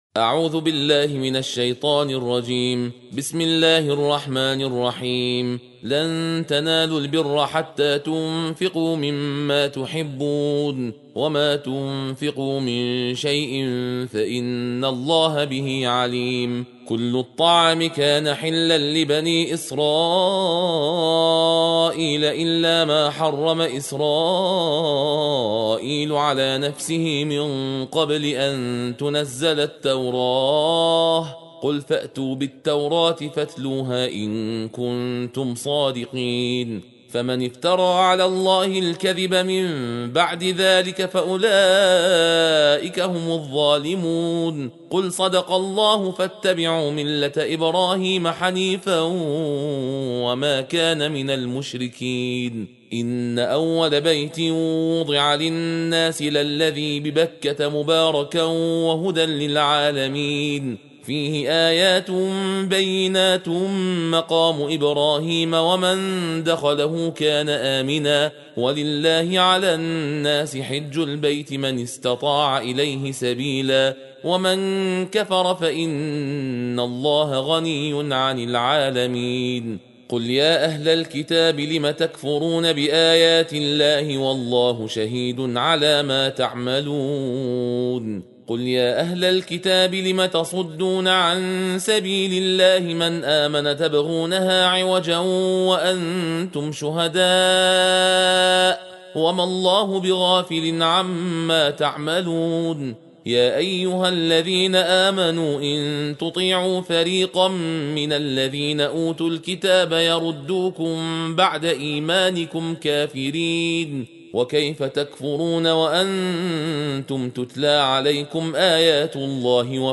در این بخش، تندخوانی جزء چهارم قرآن را برای شما آماده کرده‌ایم.
فایل صوتی جزء ۴ قرآن تندخوانی